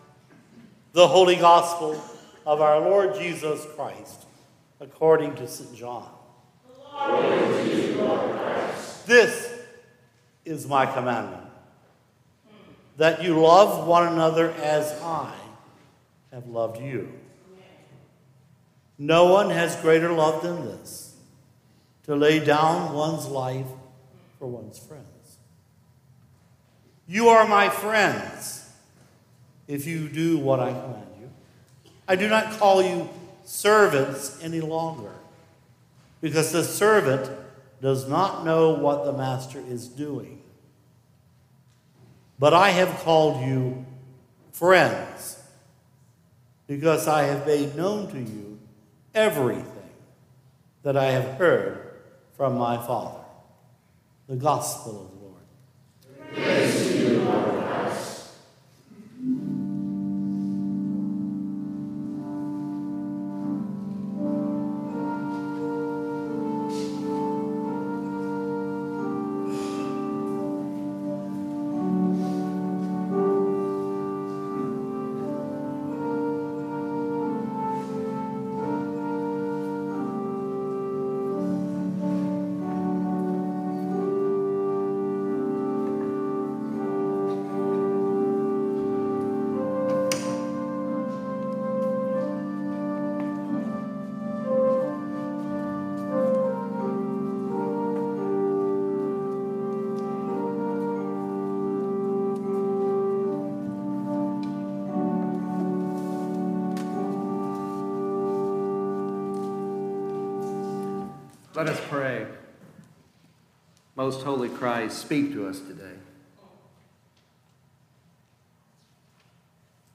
March 29, 2026 - Palm Sunday
Latest Sermons & Livestreams